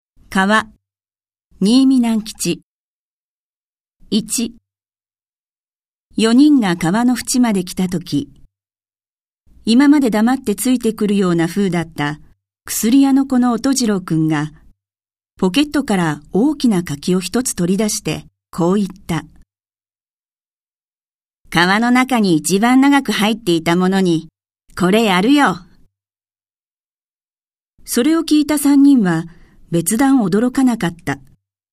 朗読ＣＤ　朗読街道123「川・赤とんぼ・巨男の話」新美南吉
朗読街道は作品の価値を損なうことなくノーカットで朗読しています。